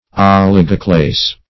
Oligoclase \Ol"i*go*clase\, n. [Oligo- + Gr.